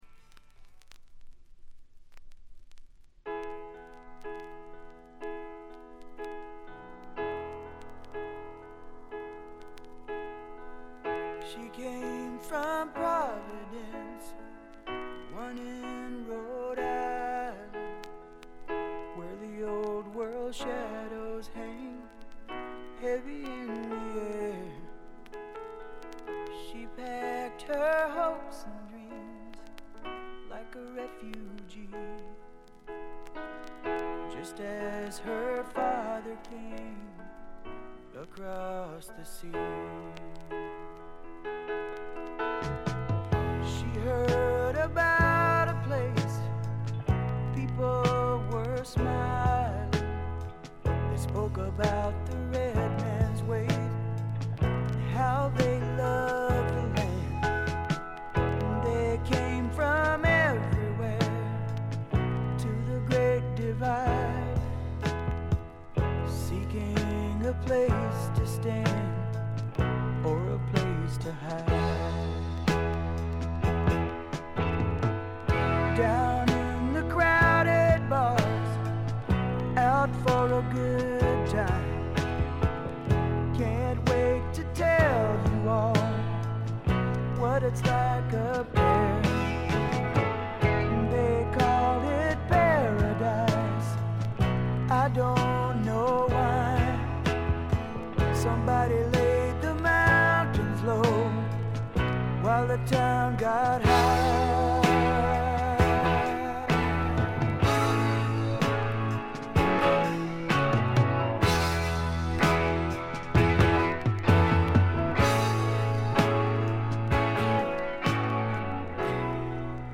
ところどころでチリプチ、静音部（A面イントロ等）でやや目立ちますが普通に鑑賞できるレベルと思います。
盤質B評価とはしましたが普通に聴けるレベルと思います。
試聴曲は現品からの取り込み音源です。